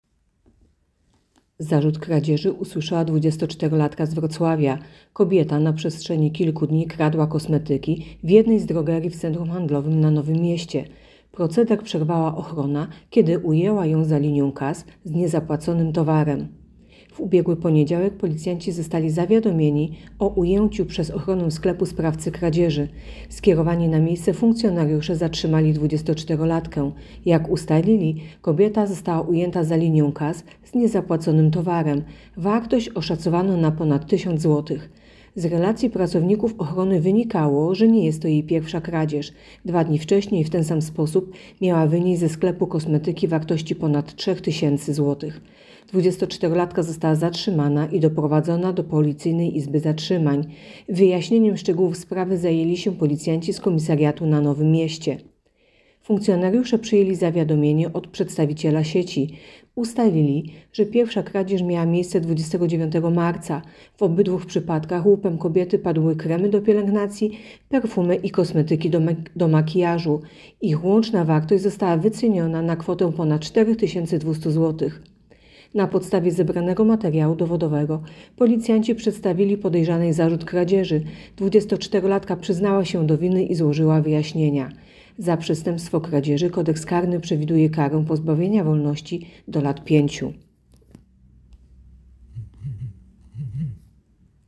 Mówi podkomisarz
Opis nagrania: Nagranie informacji pt. Kradła kosmetyki - usłyszała zarzuty.